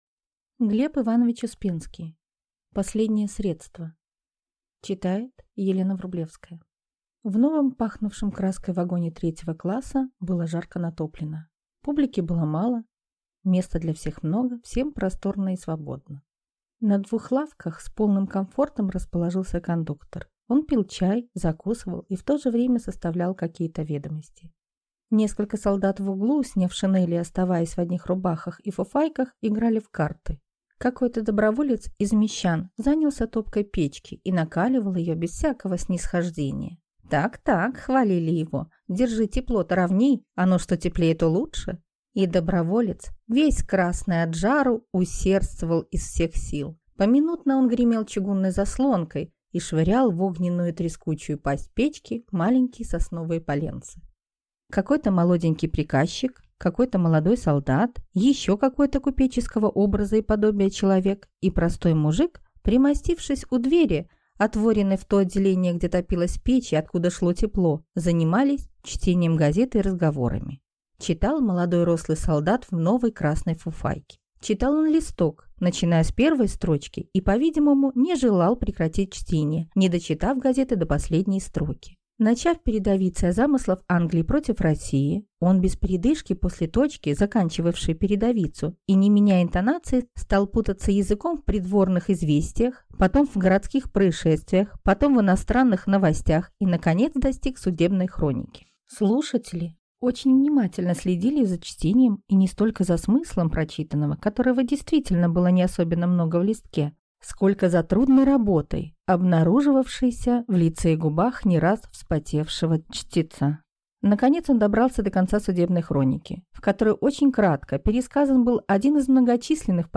Аудиокнига Последнее средство | Библиотека аудиокниг